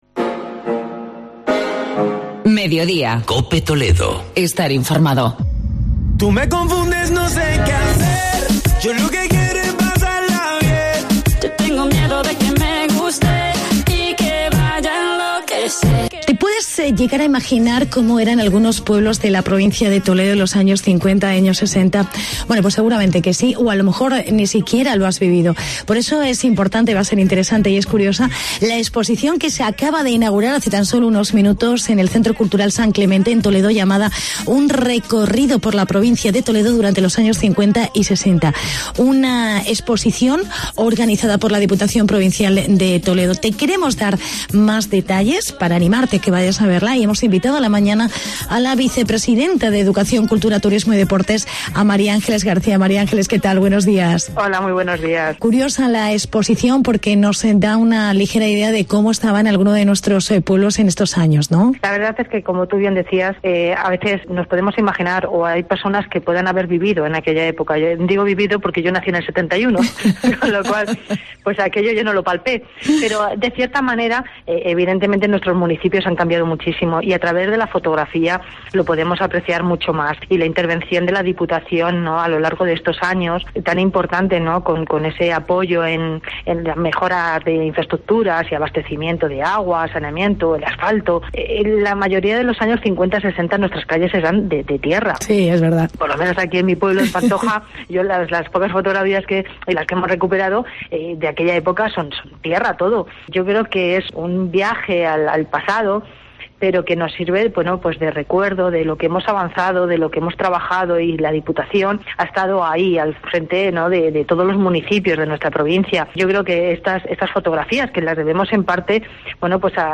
Entrevista con la diputada Mª Ángeles Garcia